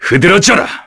Nicx-Vox_Skill4_kr.wav